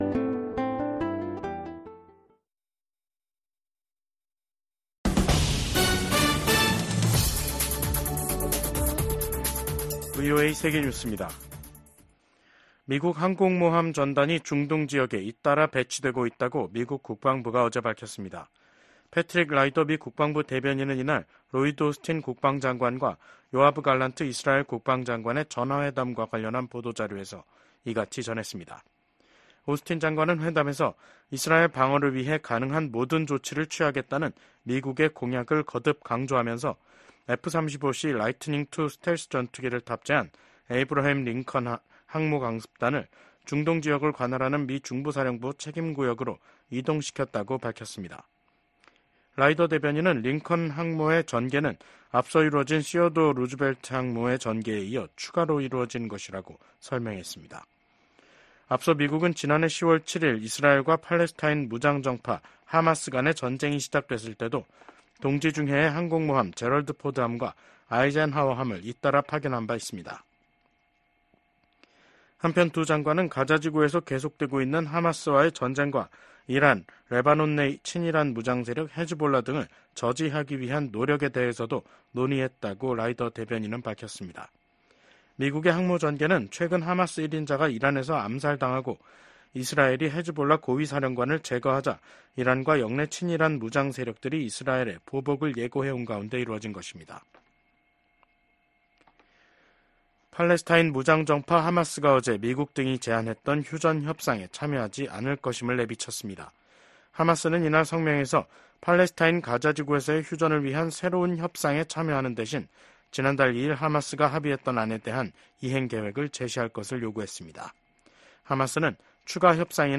VOA 한국어 간판 뉴스 프로그램 '뉴스 투데이', 2024년 8월 12일 2부 방송입니다. 미국 국무부는 제재는 긴장만 고조시킨다는 중국의 주장에 새로운 대북제재 감시 체계 마련의 필요성을 지적했습니다. 한국 군 합동참모본부와 미한연합사령부는 오늘(12일) 국방부 청사에서 열린 공동 기자회견에서 올 하반기 미한 연합훈련인 ‘을지프리덤실드’(UFS) 연습을 이달 19일부터 29일까지 실시한다고 발표했습니다.